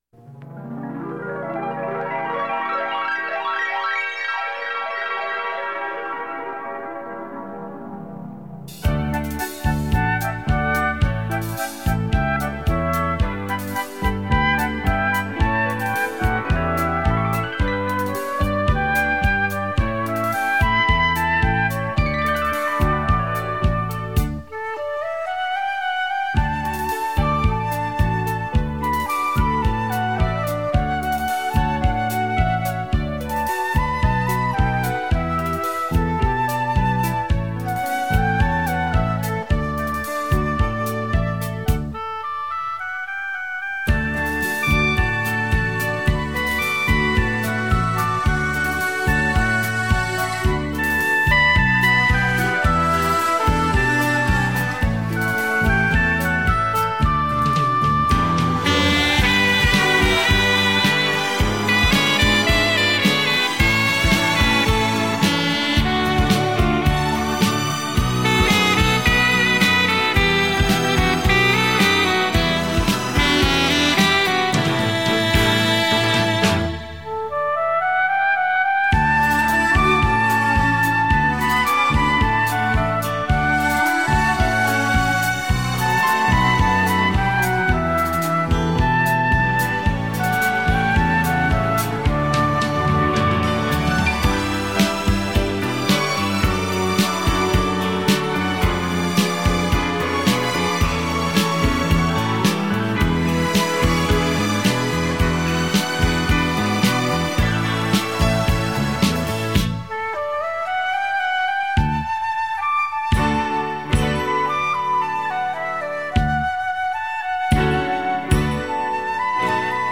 这是一篇有关于30把感情细腻的提琴